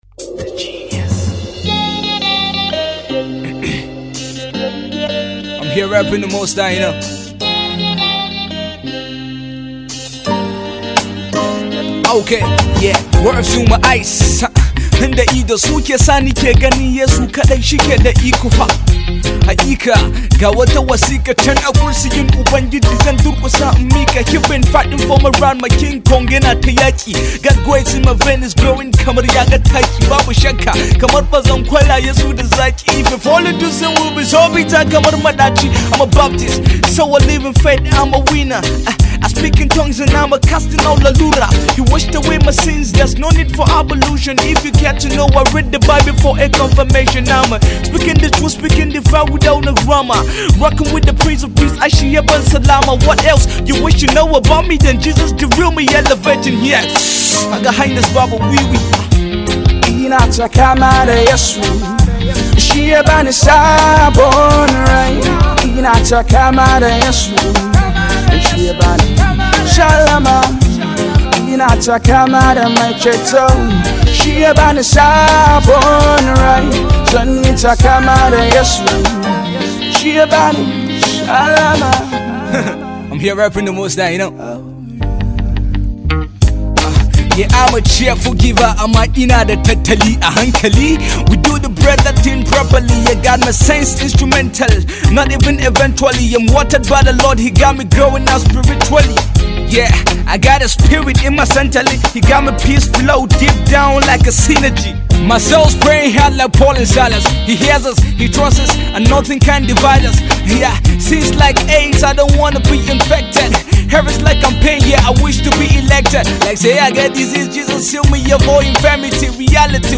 a reggae fusion